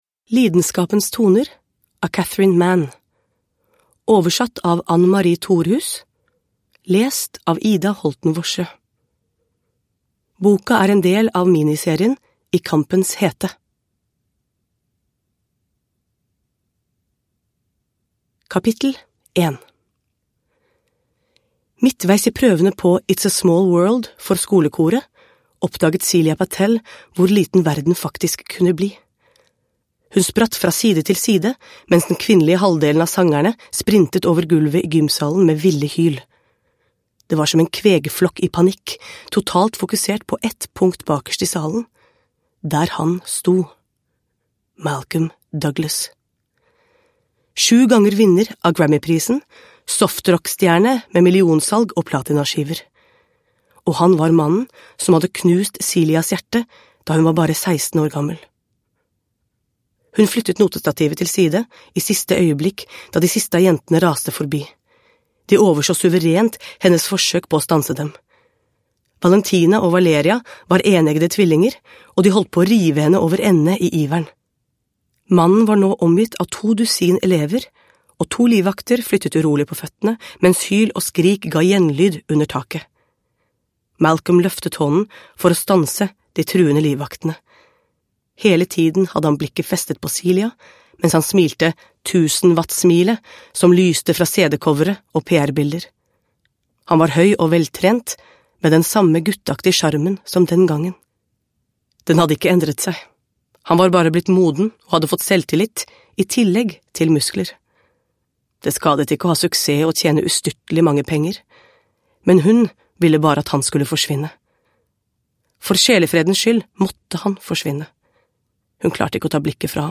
Lidenskapens toner – Ljudbok – Laddas ner